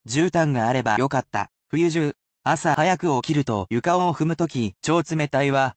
They are at a regular pace which may be difficult.
[casual speech]